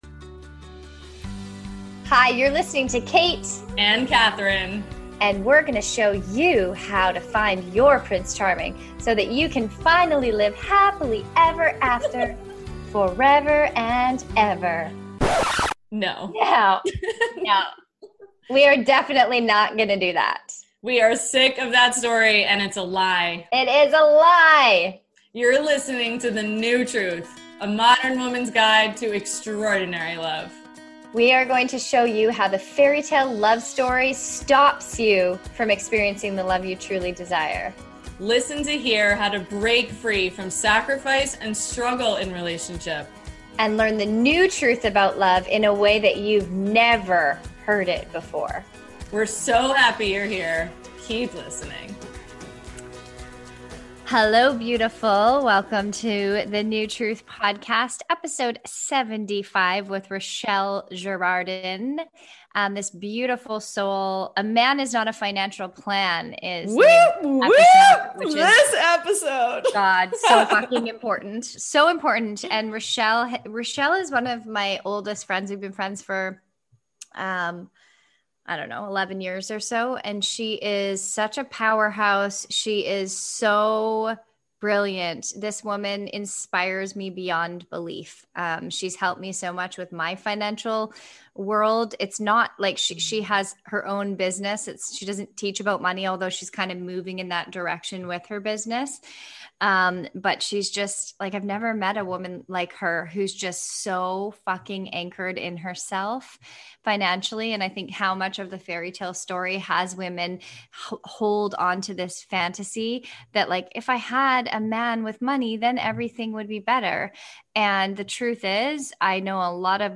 interview
Learn the link between financial freedom and soulful love and how to never end up in a situation where you can't take care of yourself. In this honest discussion we address the importance of healing your relationship with money and how to have real conversations about money when you're dating.